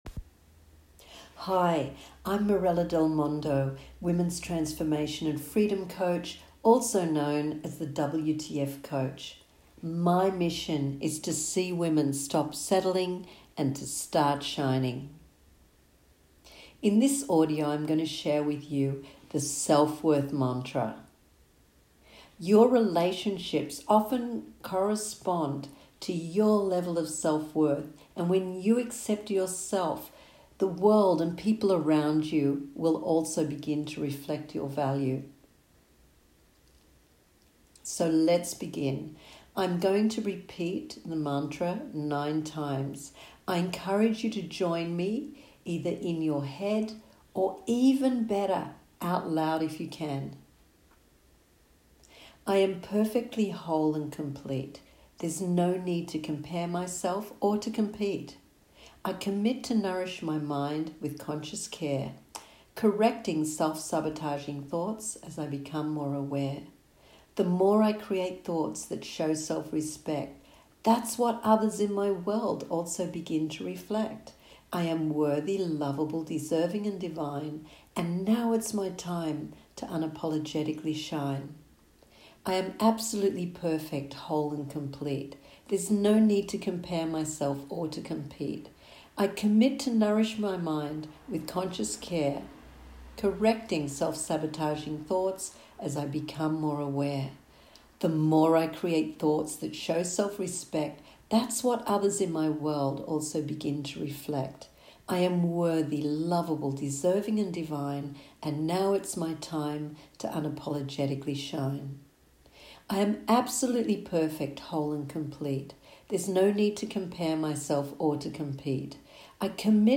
I recorded these short mantras for myself to keep me on track!
Self Worth Mantra
Self+worth+Mantra.m4a